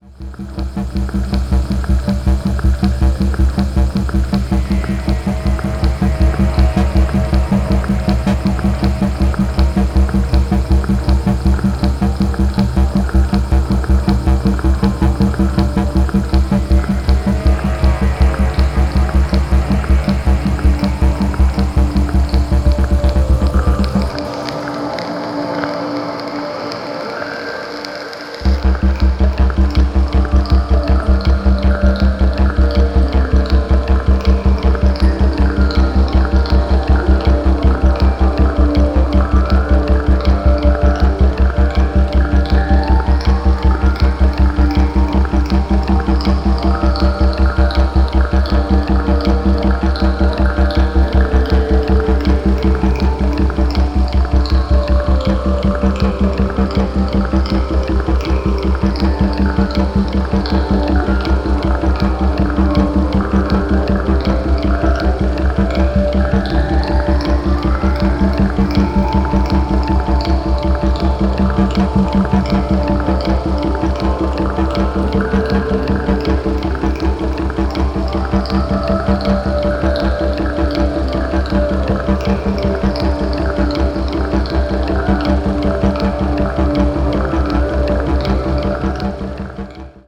widescreen machine soul
electro, techno, ambient and jungle